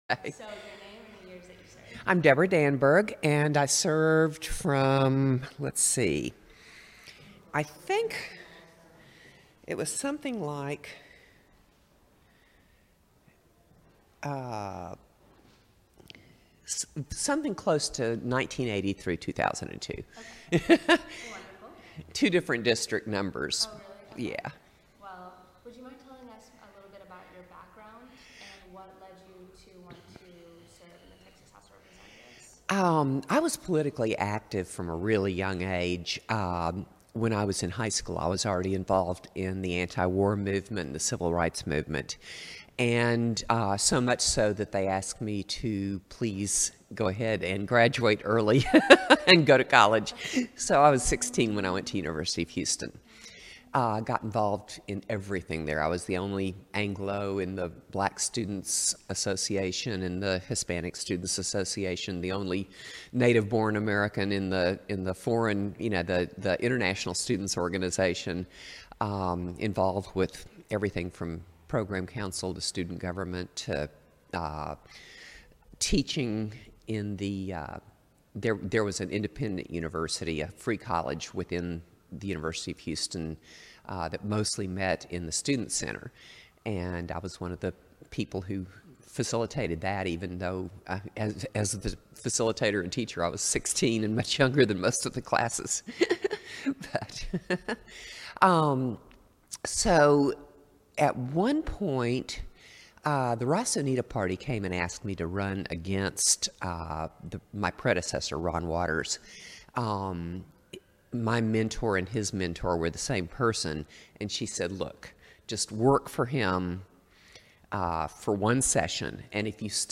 Oral history interview with Debra Danburg, 2015. Texas House of Representatives .